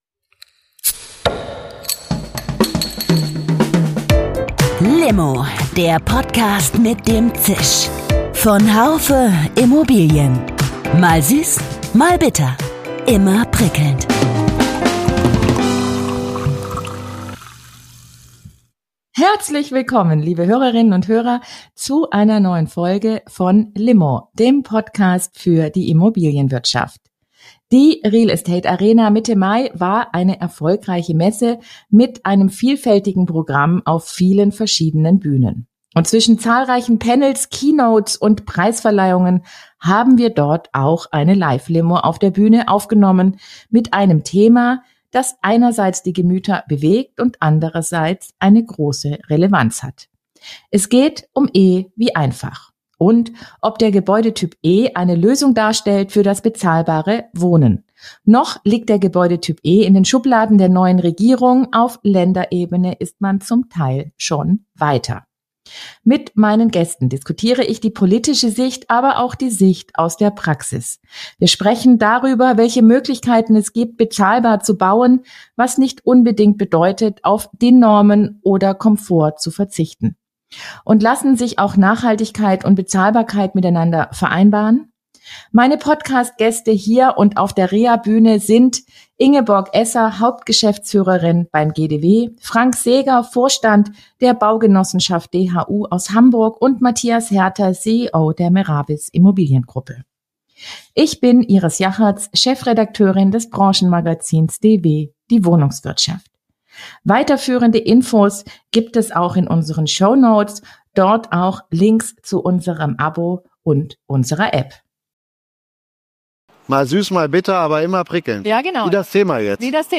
E wie einfach - bezahlbares Bauen und Wohnen - live von der Real Estate Arena ~ L'Immo – Der Podcast für die Immobilienwirtschaft Podcast